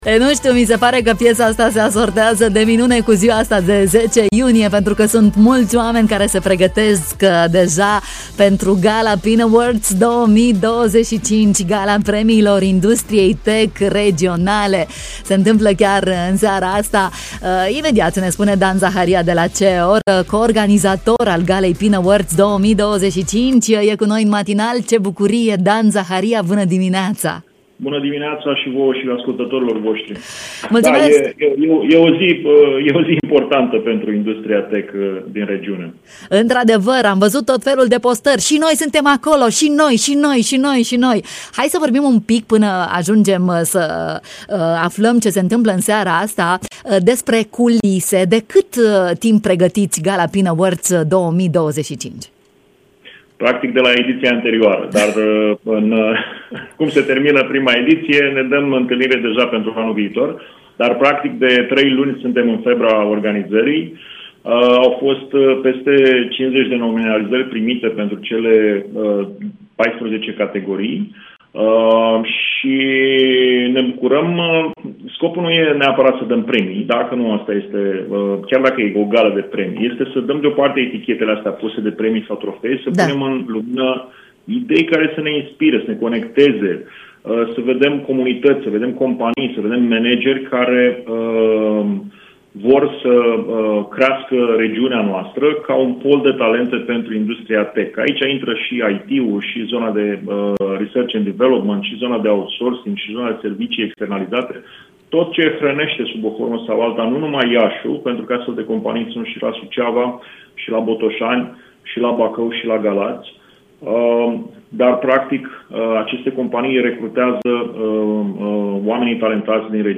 în matinal